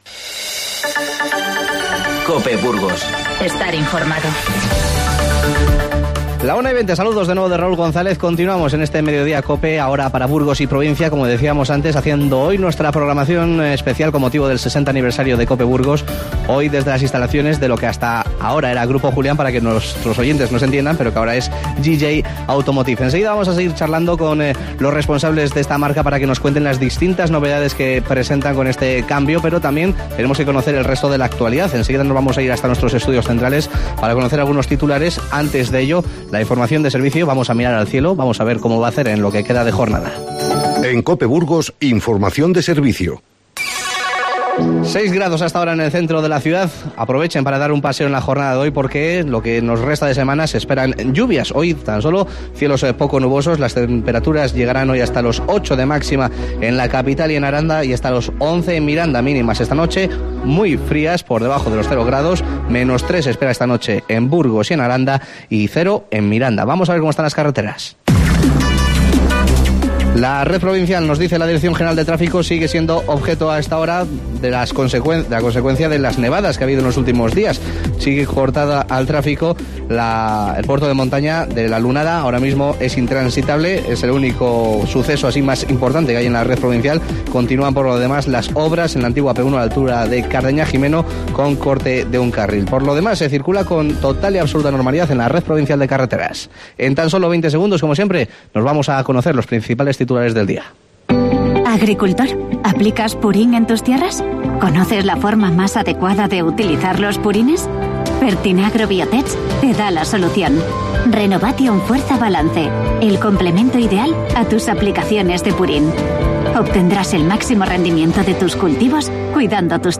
Hoy hacemos nuestra programación local desde GJ Automotive.